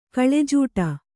♪ kaḷejūṭa